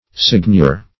Signior \Sign"ior\, n.